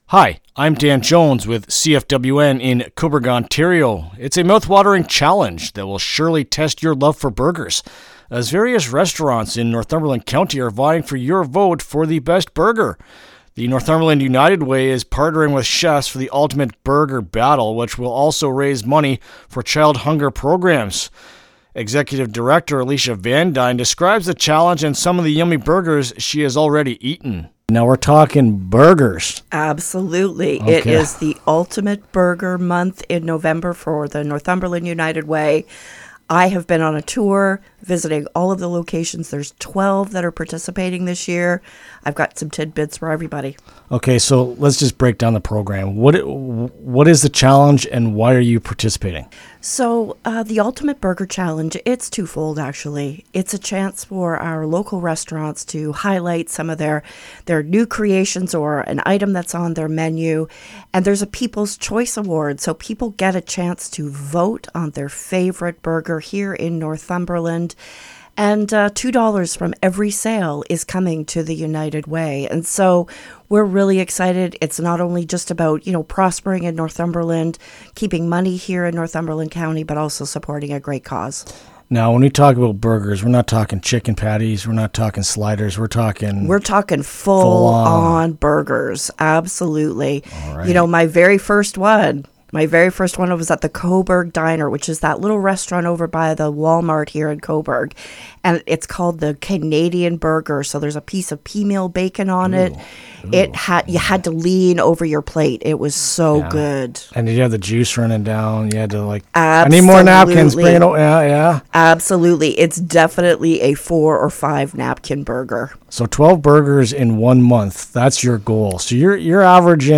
Ultimate-Burger-Battle-Interview-LJI.mp3